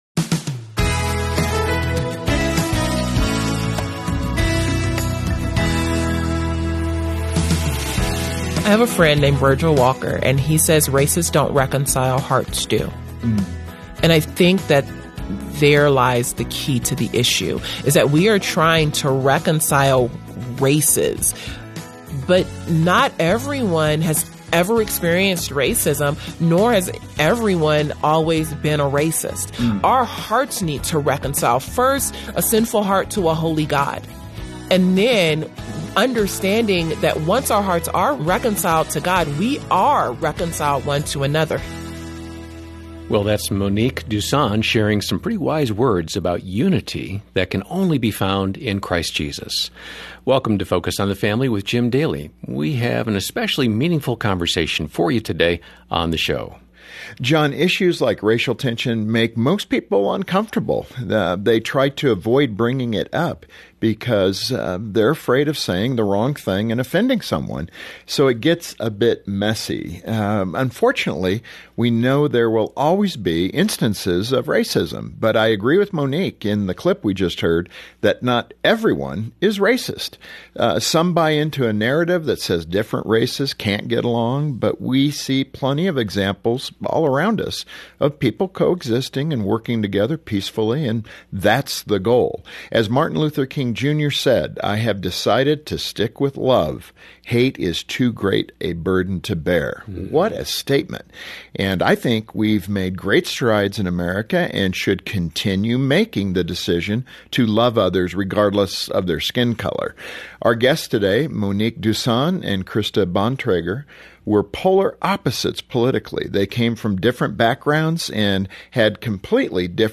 Focus on the Family Daily An Unlikely Multi-Ethnic Friendship Sealed in Christ Play episode August 26 26 mins Bookmarks Episode Description Two Christian women from different political perspectives and cultural backgrounds describe an unlikely but beautiful friendship that grew from having honest conversations about race. They’ll offer inspiration and practical help for you to build bridges with others.